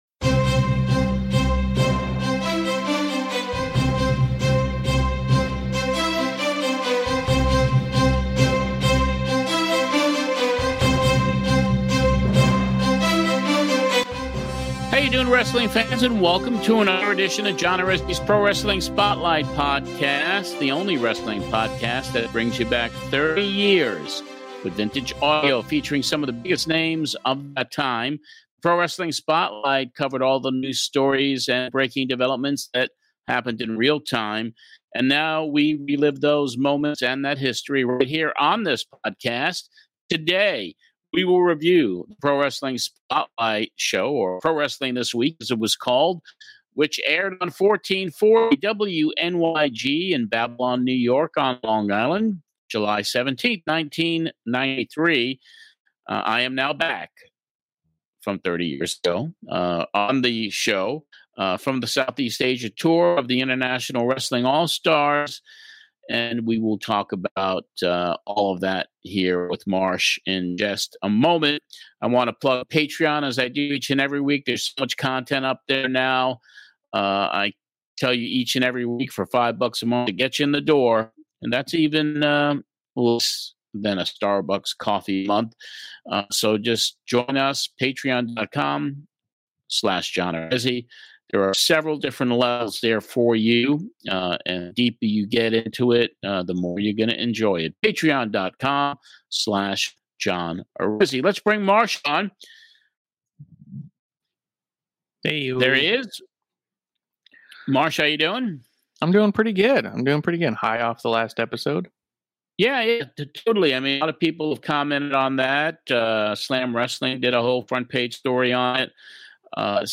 Other callers
commercials and much more.